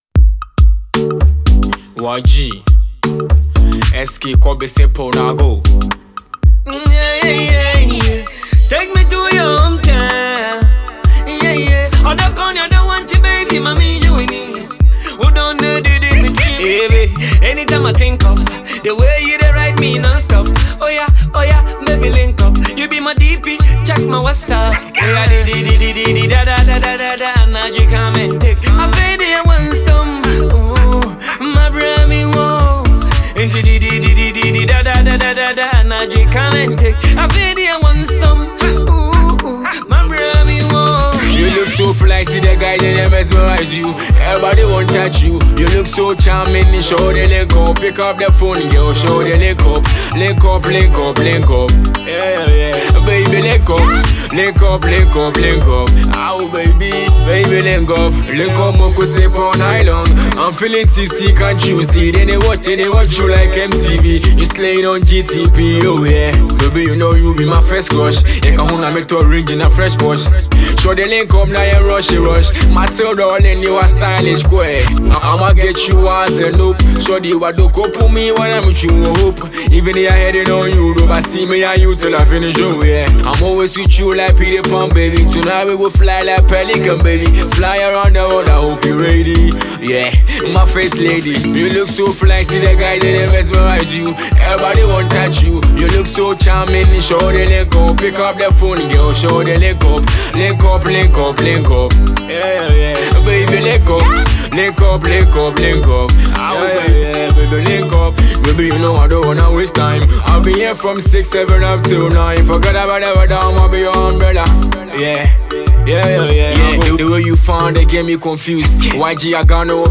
rapper and singer
highlife